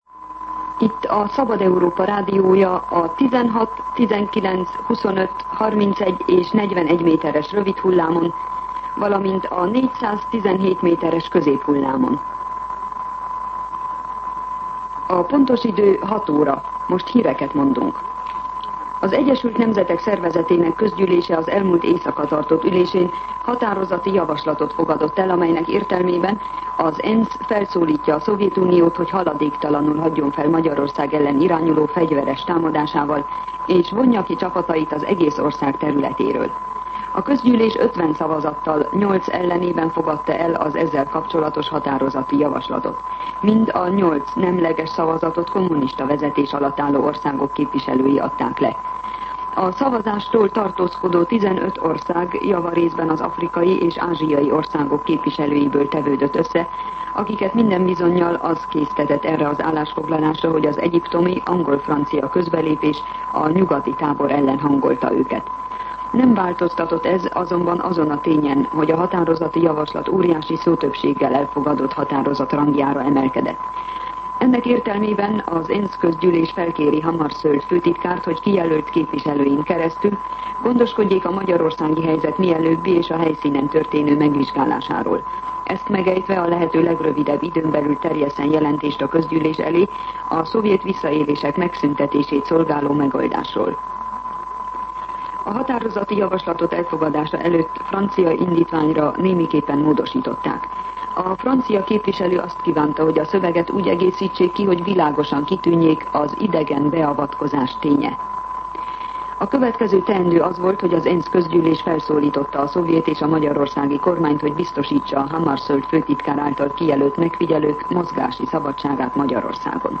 06:00 óra. Hírszolgálat